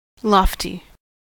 lofty: Wikimedia Commons US English Pronunciations
En-us-lofty.WAV